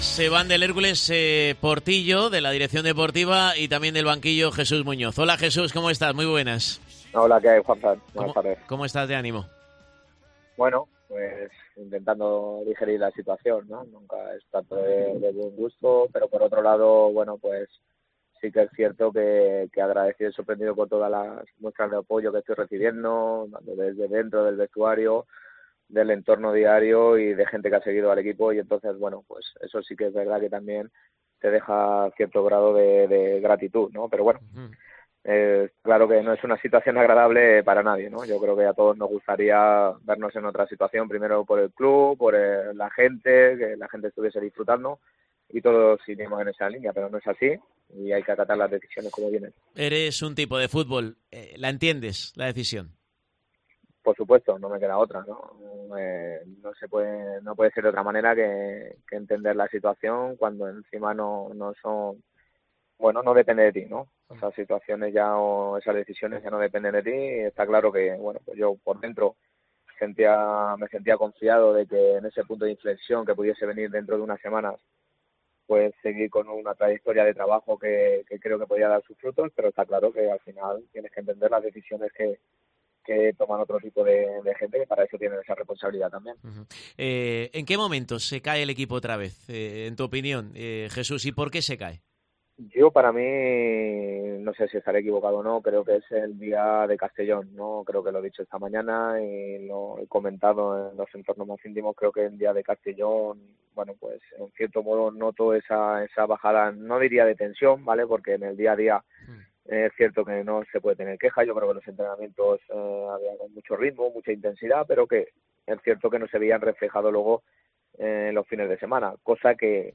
Escucha la entrevista al técnico, que explica por qué cree que el equipo no ha logrado salir de la crisis de resultados.